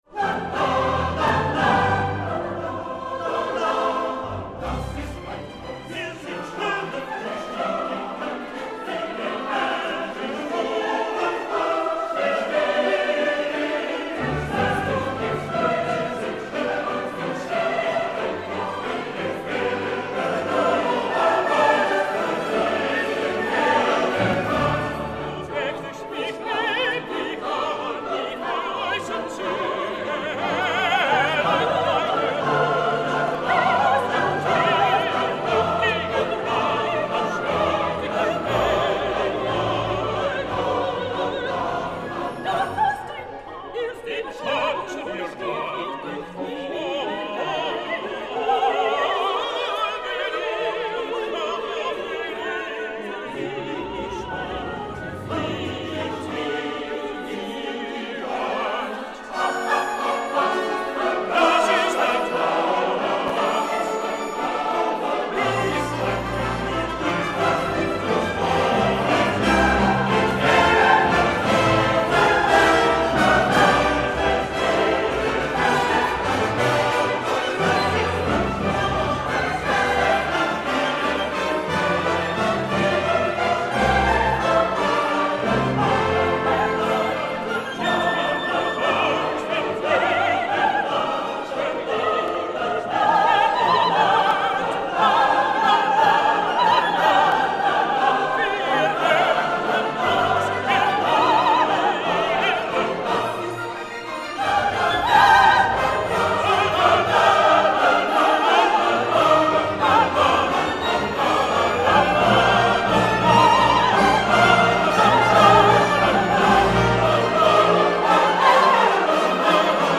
A Mass of Life—scored for three soloists (SAT), two choruses, and orchestra—is in two parts, further divided into five and six sections, respectively.
Part I, no. 3 consists of relaxed outer sections that frame an interior, vigorous dance.
At the height of the movement, the three solo voices and the two mixed choruses sing (with orchestral accompaniment) what may be loosely described as a fugal dance-song without text, a whole interlude on the syllable “la” with occasional interjections of the phrase “Das ist ein Tanz!” [“Now for a dance!”].